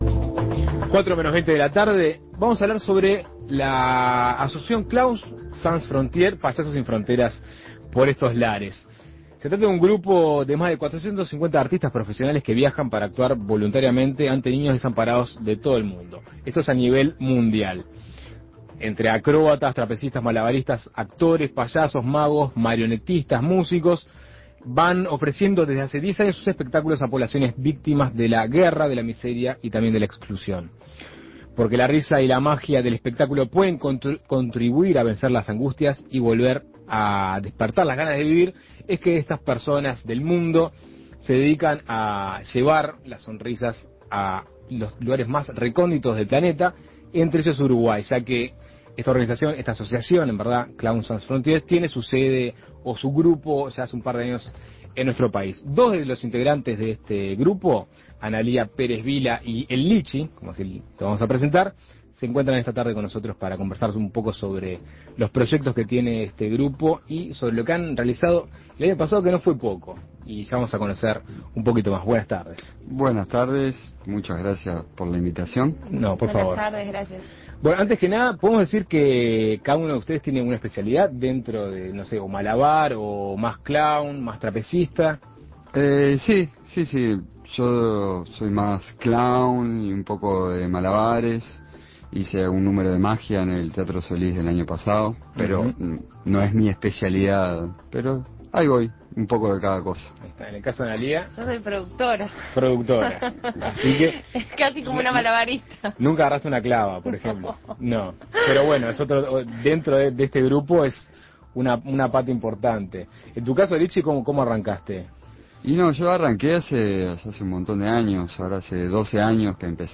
Desde hace 10 años la Asociación Payasos Sin Fronteras viaja alrededor del mundo llevándole alegría a los niños desamparados. Dos de sus integrantes visitaron Asuntos Pendientes para profundizar en el tema.
Entrevistas Payasos Sin Fronteras en Asuntos Pendientes Imprimir A- A A+ Desde hace 10 años la Asociación Payasos Sin Fronteras viaja alrededor del mundo llevándole alegría a los niños desamparados.